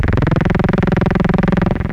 • monodelay42 - Dm - 135.wav
Loudest frequency 442 Hz Recorded with monotron delay and monotron - analogue ribbon synthesizer